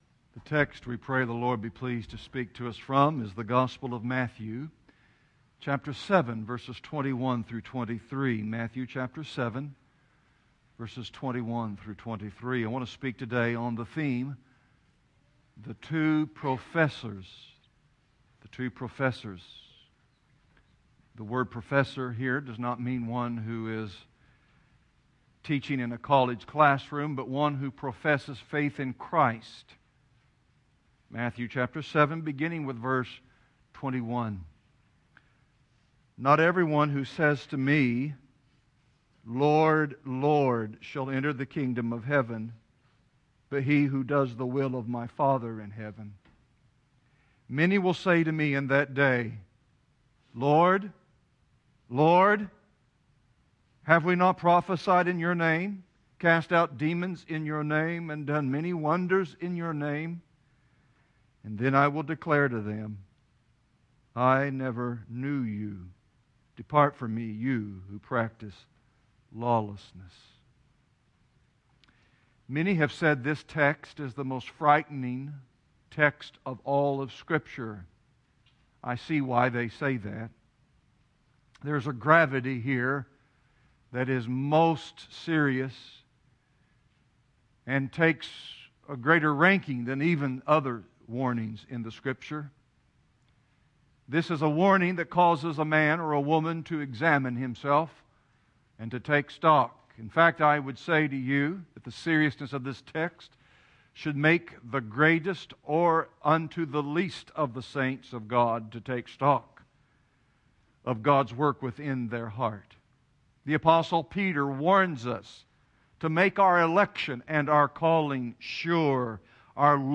Sermon on the Mount Matthew 7:21-23